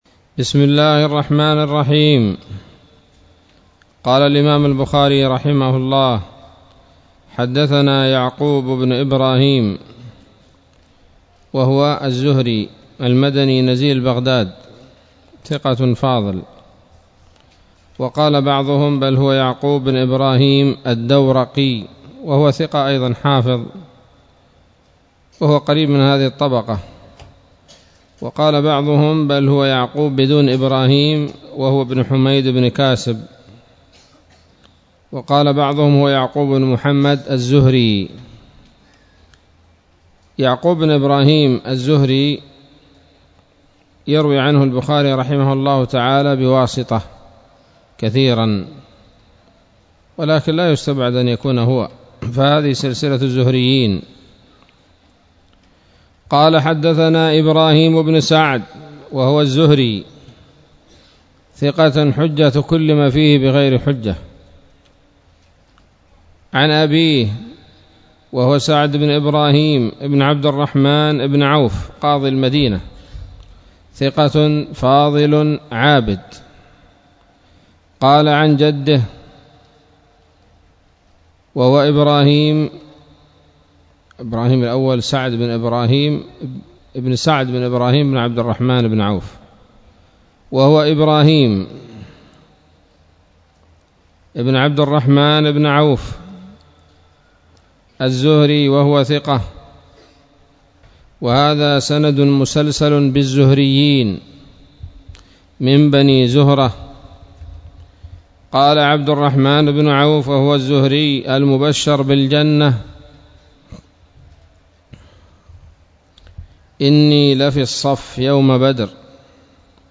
الدرس الخامس عشر من كتاب المغازي من صحيح الإمام البخاري